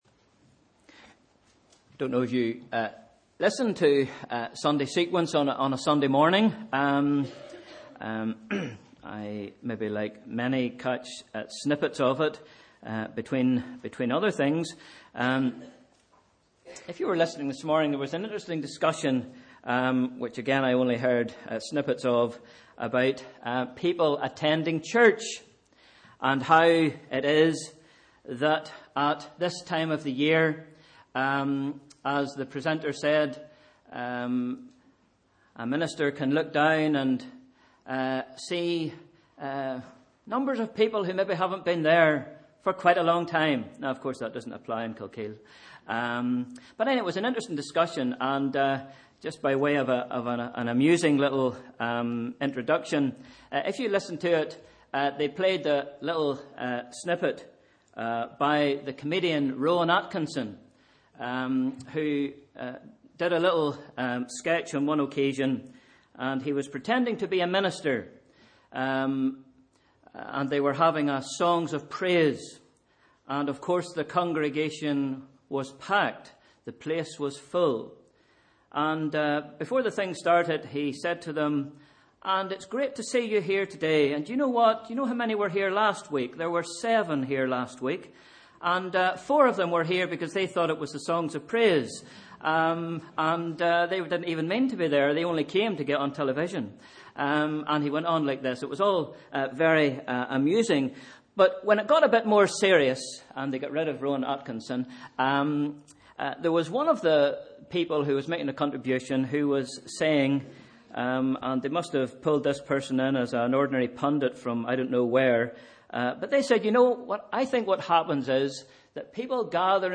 Sunday 13th December 2015 – Morning Service